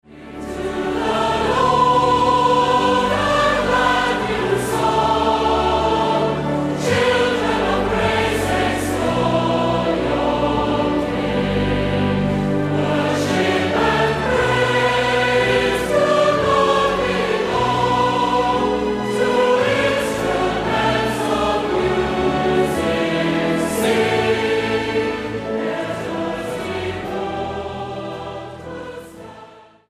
All Souls Orchestra - I Will Sing The Wondrous Story: The Best Of Prom Praise Hymns & Songs
STYLE: Hymnody